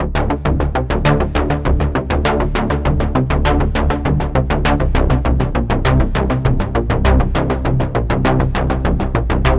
卡式音乐磁带声音
描述：卡式音乐磁带声音。一个有趣的90年代合成器和弦跟踪“追踪”的声音加入。
标签： VHS 卡式 俊俏的 磁带 90年代 滑稽 音乐